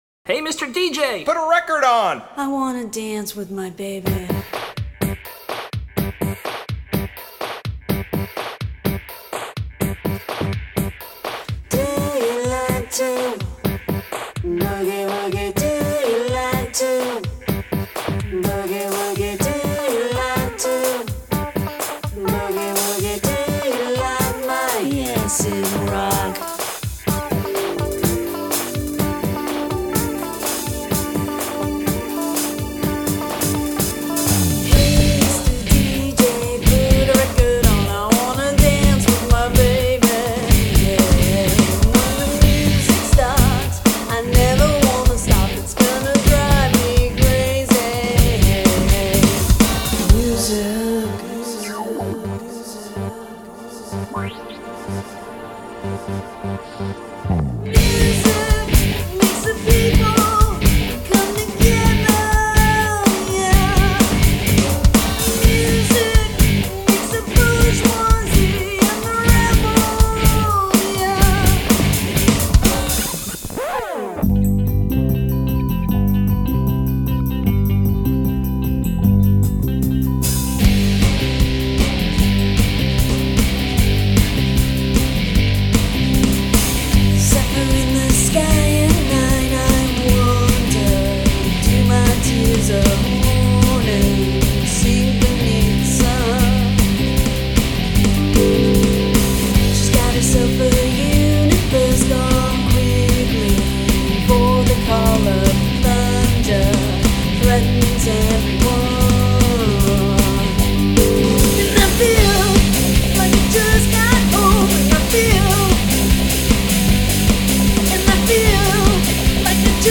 Vocal, Guitar, Sequence
Bass, Vocal
Drums, Vocal, Sequence